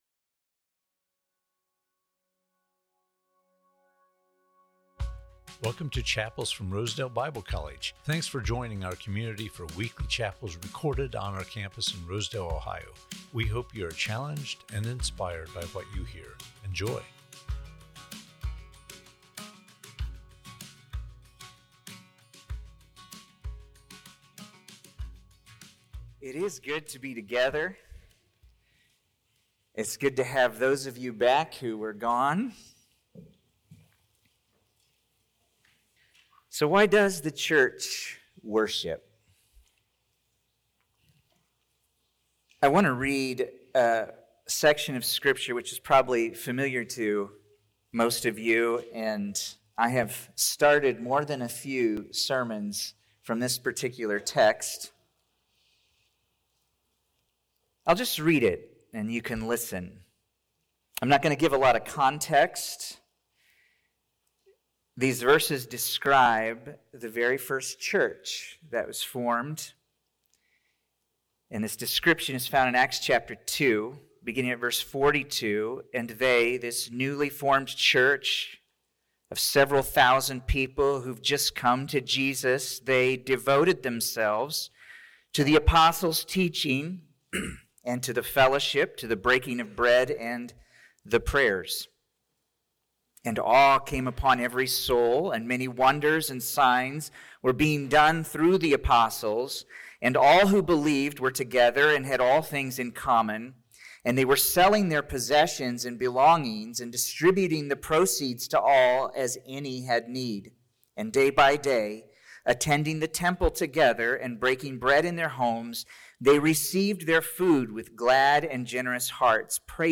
Chapels from Rosedale Bible College